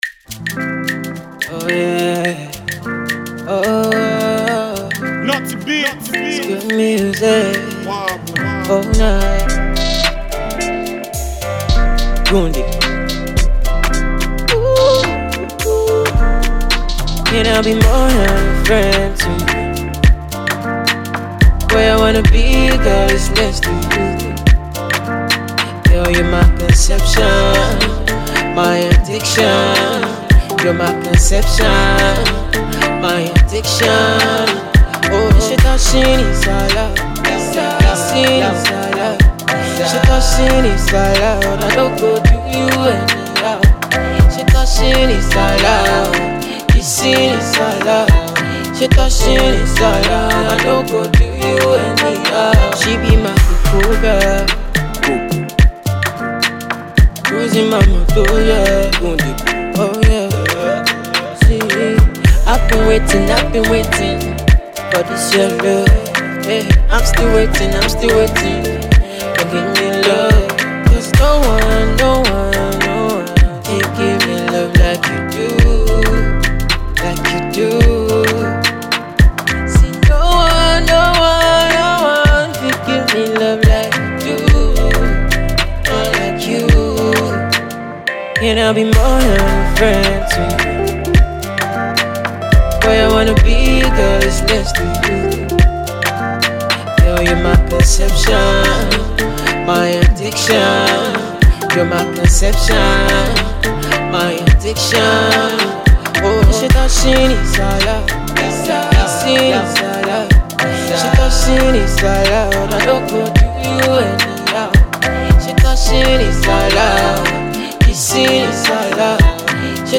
an Afropop fused with trap tune
unique vocals